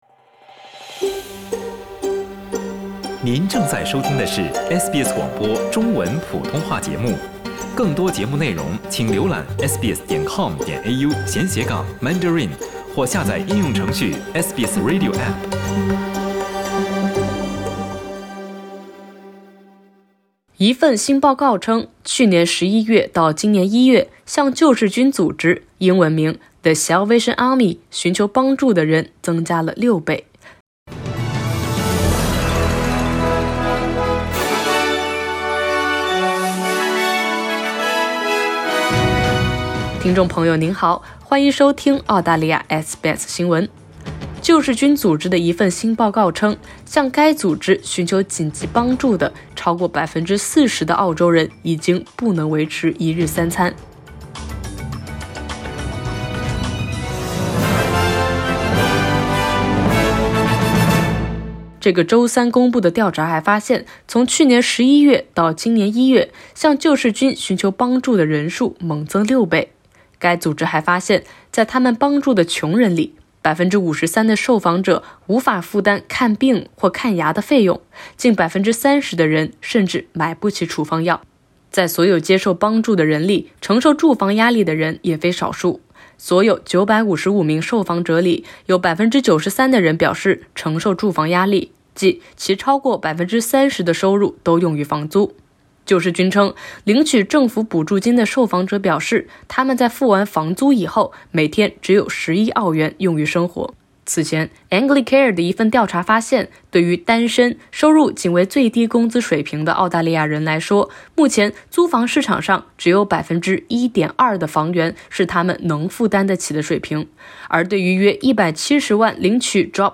该机构还预计，与疫情前相比，在补助金减少的情况下，将会有额外大约58万人生活在贫穷线之下。（点击上图收听报道）